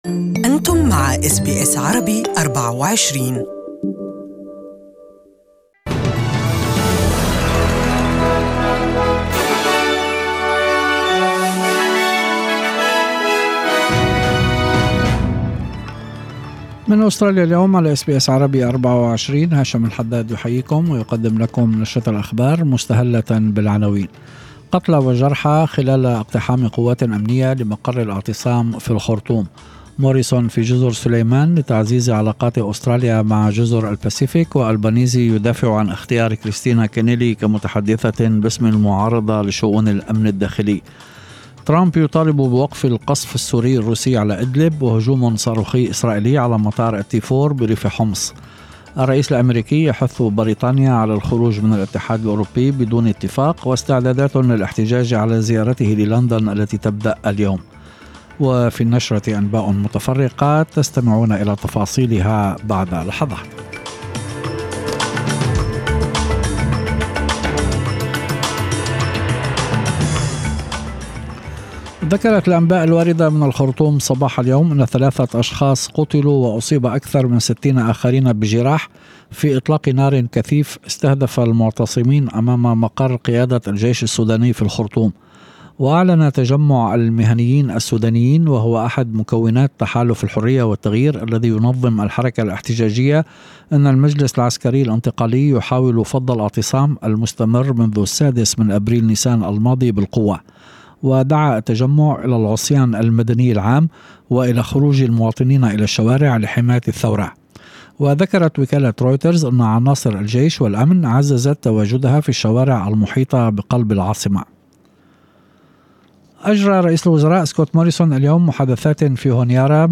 Evening News: Ten killed as Israel strikes Syria in response to rocket attacks